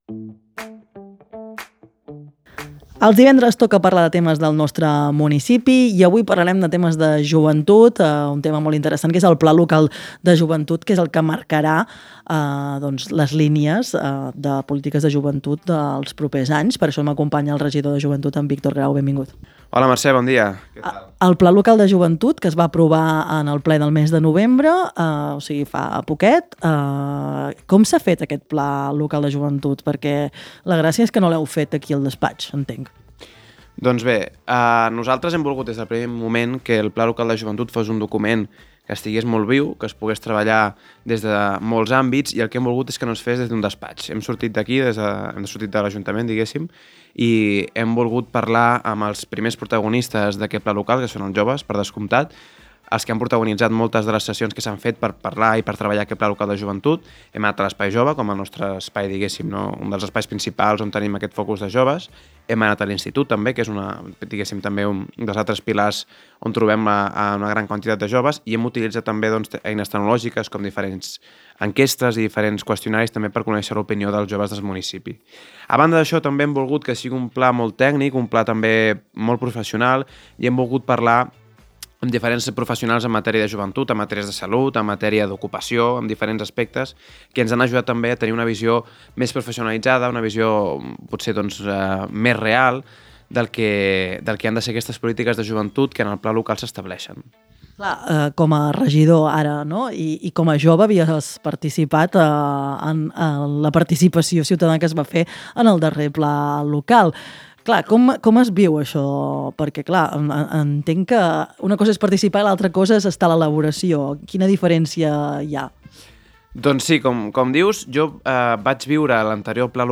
A Les Veus del Matí hem conversat amb Víctor Grau, regidor de Joventut de l’Ajuntament de Vilafant, per analitzar el recent Pla Local de Joventut, aprovat aquest mes de novembre.